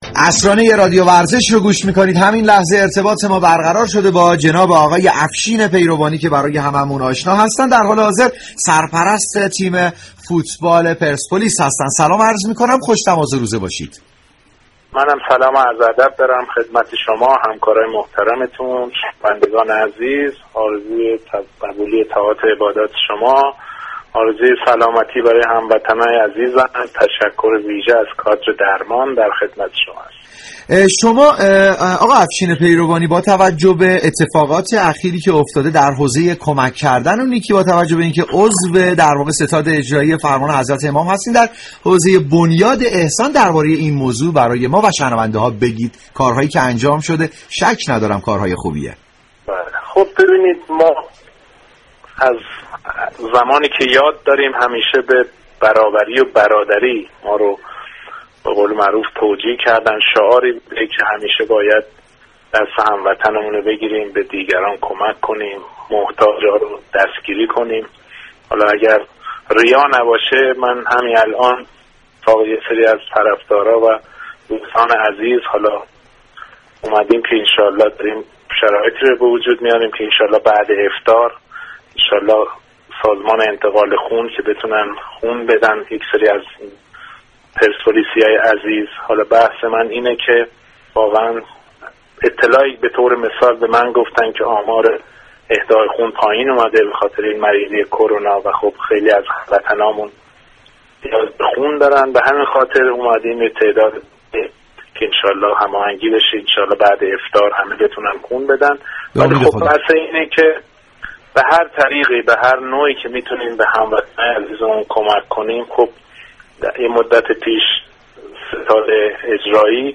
افشین پیروانی، سرپرست تیم فوتبال پرسپولیس در گفتگو با برنامه عصرانه رادیو ورزش از مشاركت در توزیع سی هزار بسته حمایتی به نیازمندان شیراز خبر داد.